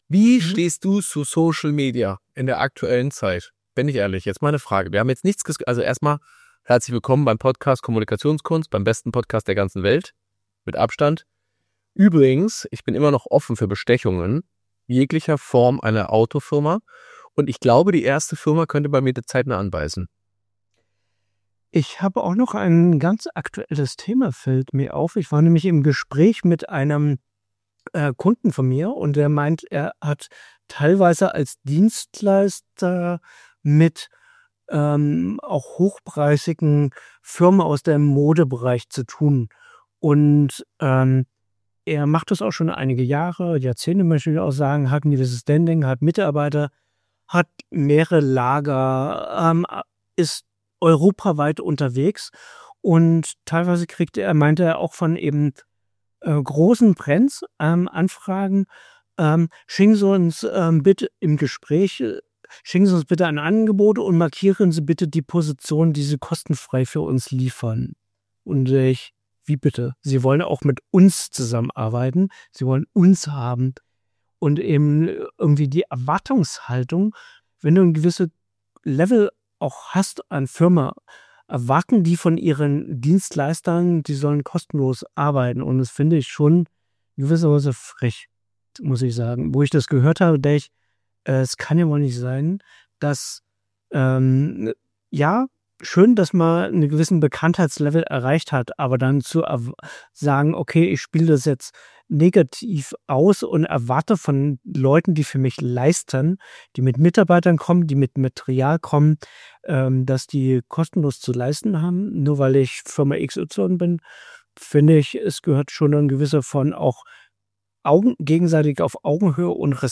Kein Tisch mehr, dafür mehr Offenheit, mehr Gespräch, mehr Dynamik. Und genau das spiegelt auch den Inhalt dieser Folge wider: Ein freier, ehrlicher und ungefilterter Austausch über das, was uns aktuell unternehmerisch bewegt.
Diese Folge ist anders: weniger Struktur, mehr Realität.